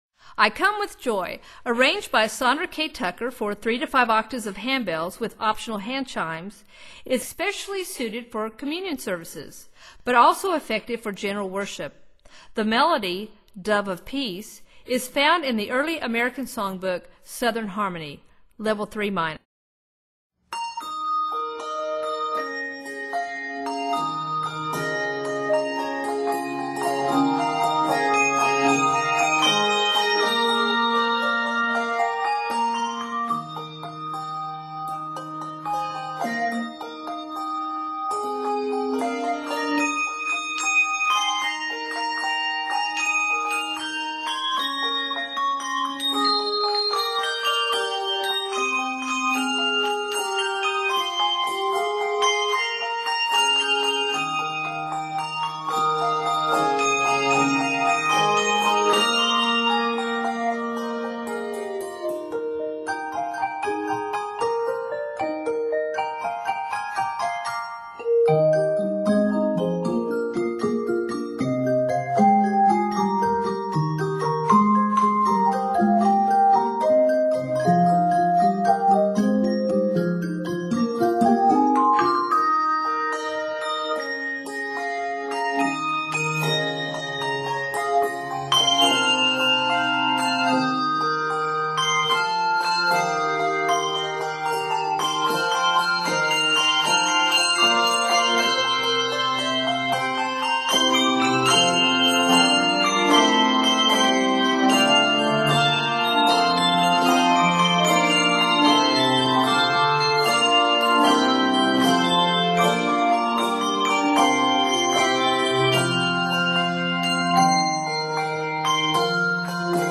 American folk tune